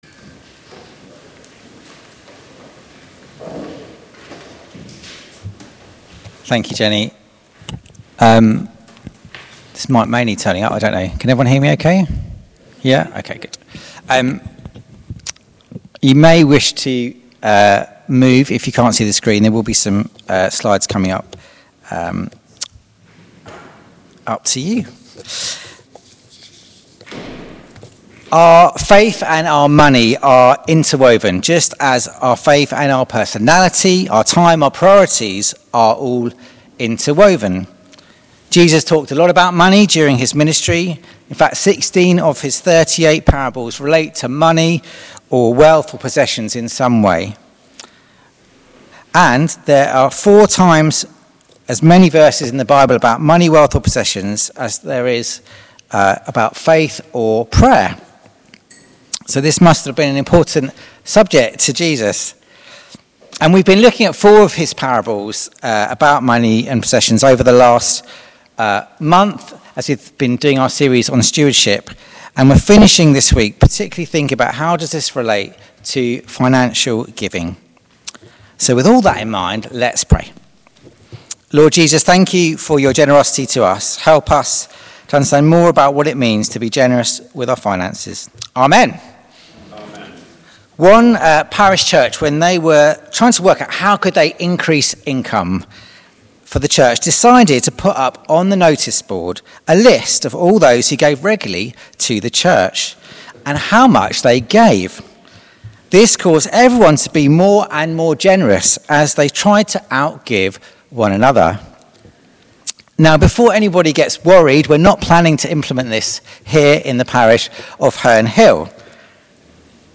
Please listen to our 8am Sermon here: